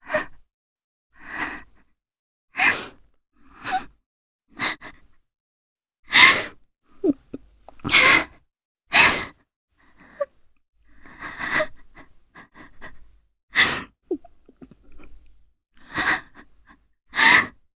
Cassandra_Vox_Sad_jp.wav